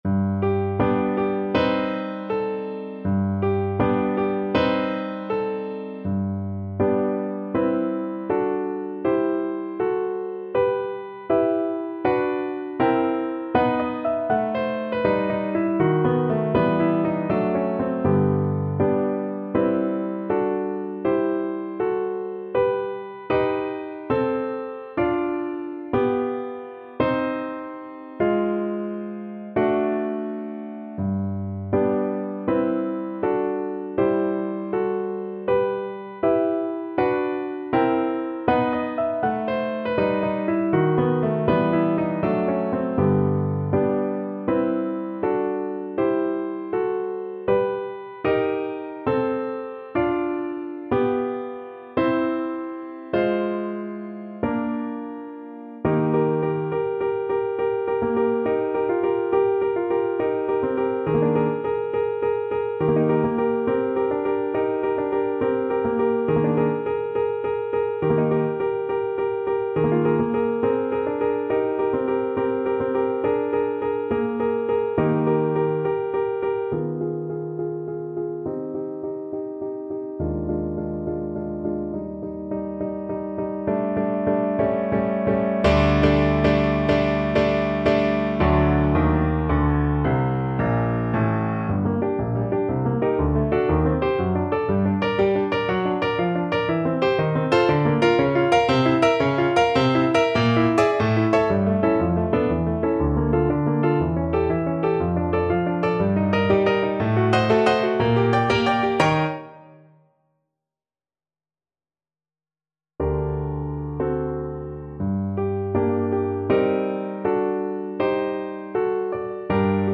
2/4 (View more 2/4 Music)
Moderato =80
Classical (View more Classical Violin Music)